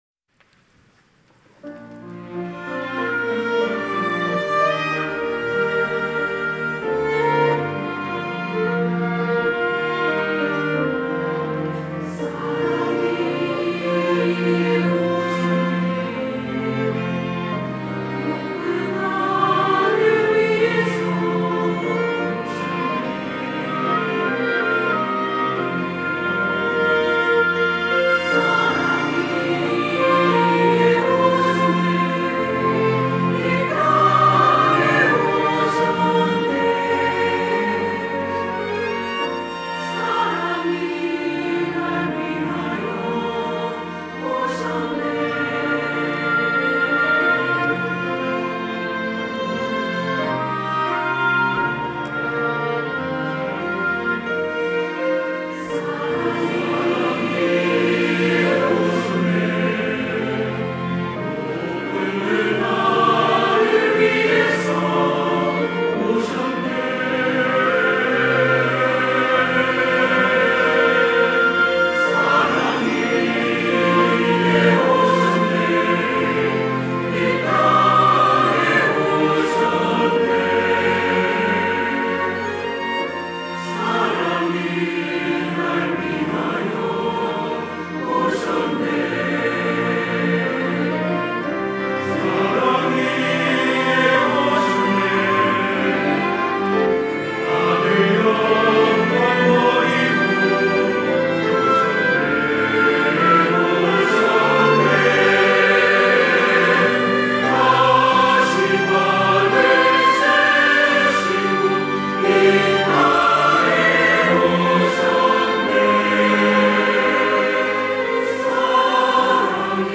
호산나(주일3부) - 사랑이 예 오셨네
찬양대